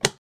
enemy_hit.ogg